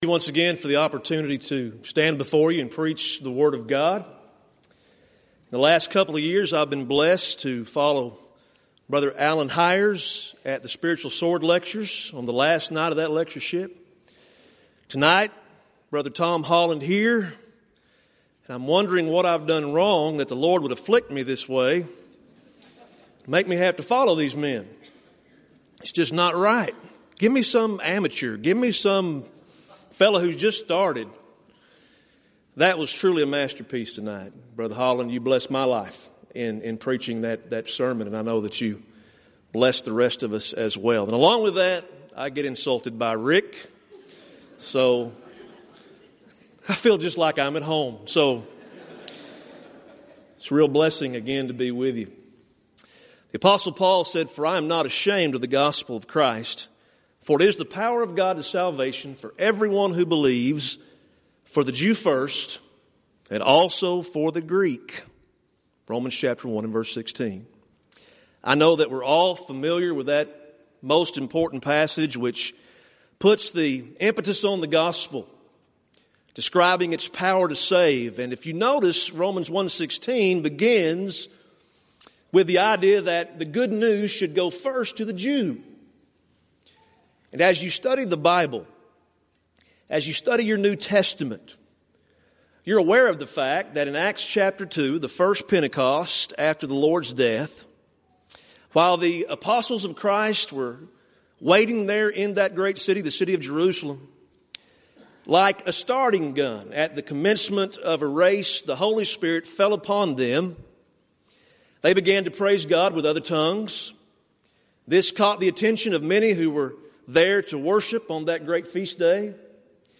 Event: 29th Annual Southwest Lectures